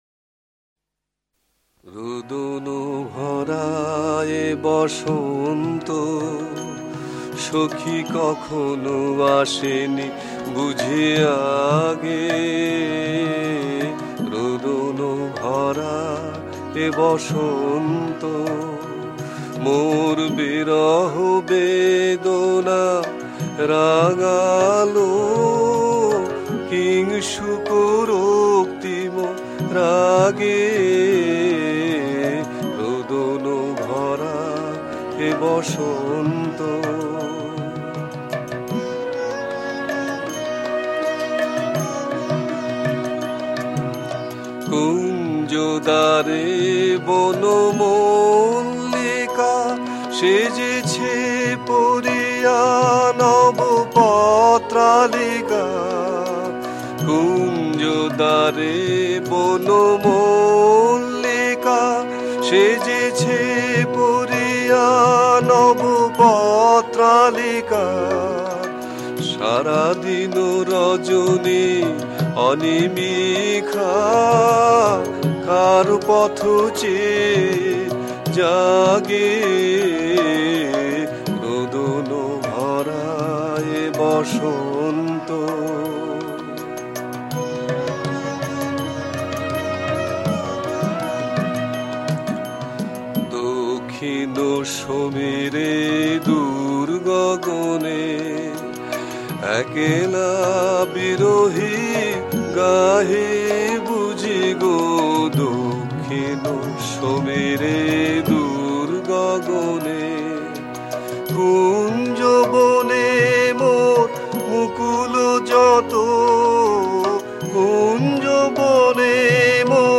Genre Rabindra Sangeet